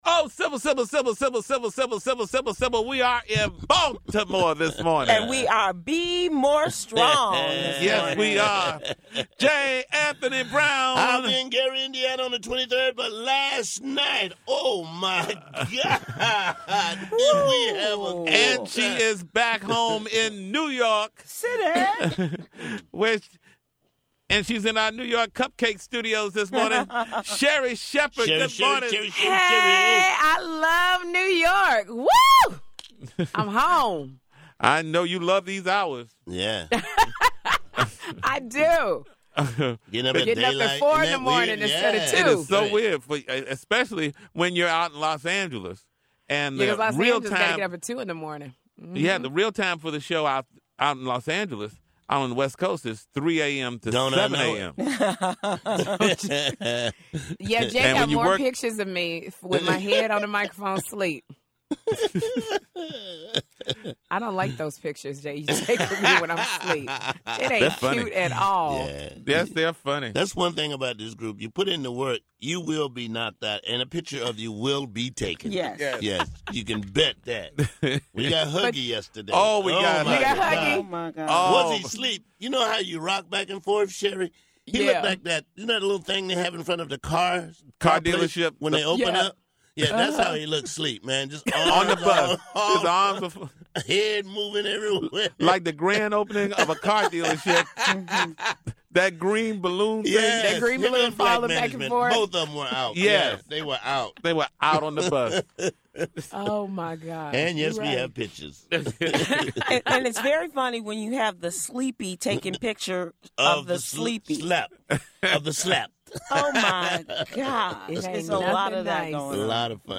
5/14/15 – The TJMS crew is live from Baltimore and it’s Sherri Shepherd Thursday! Click the link above to hear the Tom, Sybil, J. and Sherri discuss why it’s hard out here by yourself.